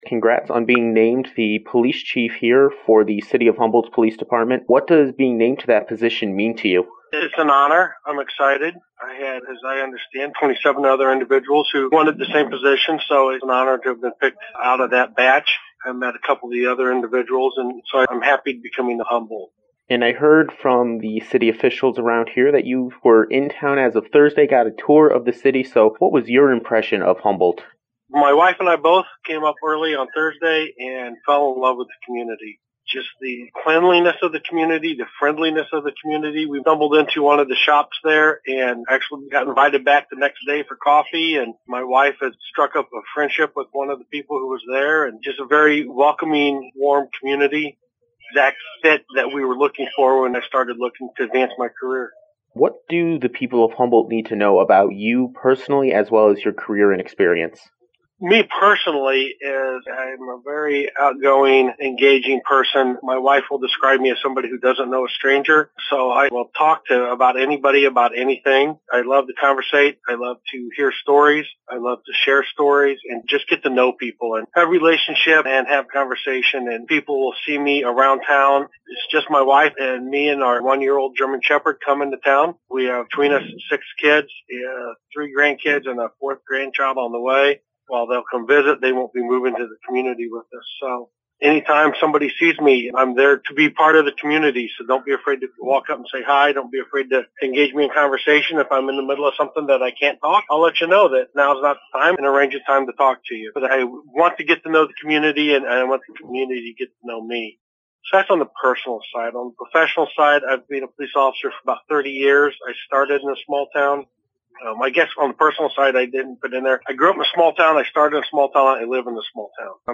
Full interview with Joel Sanders: